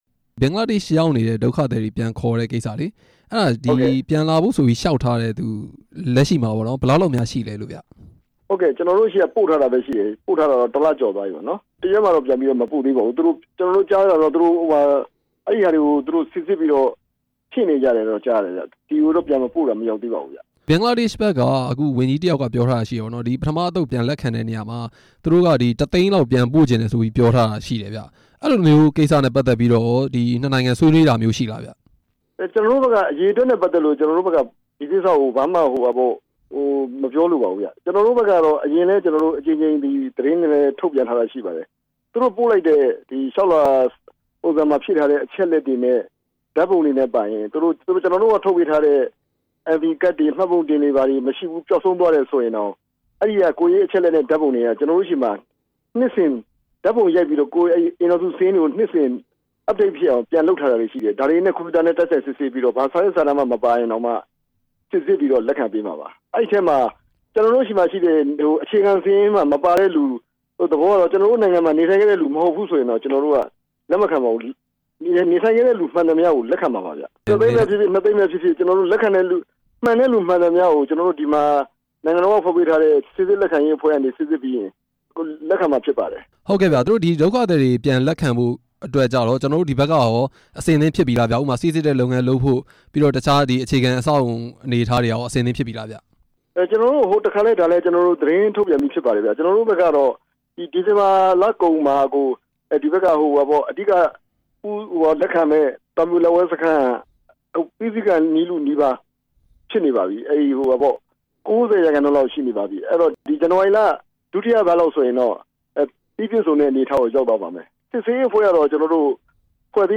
ဒုက္ခသည်တွေ ပြန်လည် လက်ခံရေး အမြဲတမ်းအတွင်းဝန် ဦးမြင့်ကြိုင်နဲ့ မေးမြန်းချက်